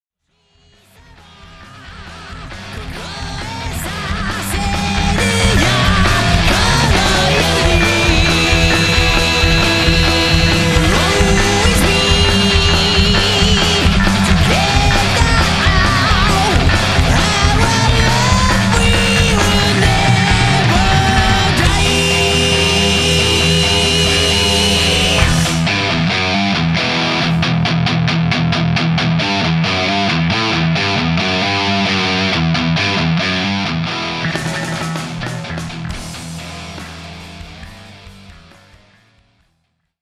ハード・ロック・サウンドが身上だ。